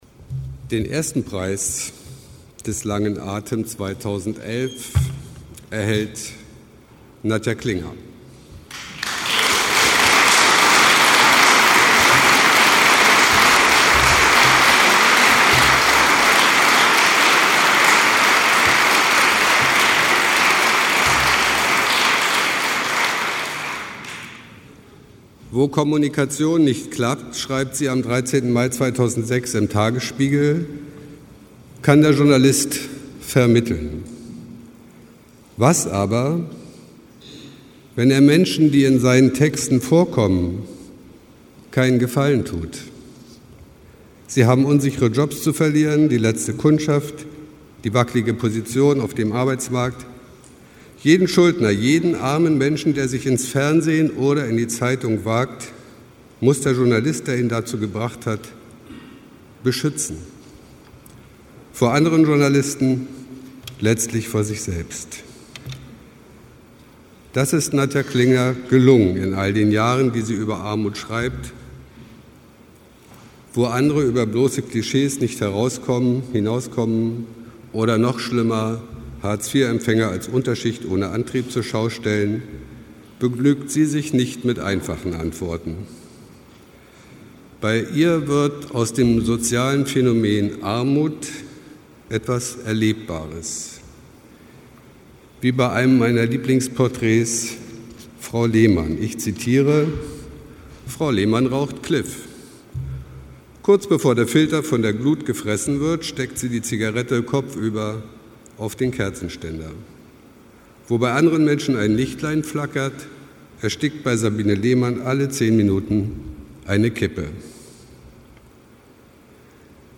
Laudatio
Was: Journalistenpreis „Der lange Atem“ des JVBB, Landesverband des DJV
Was: Berlin, Radialsystem